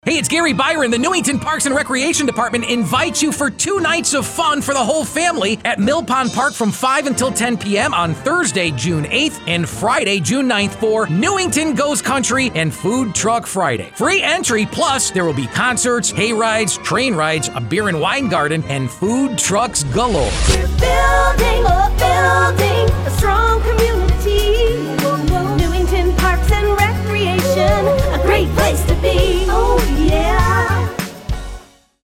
Our Commercial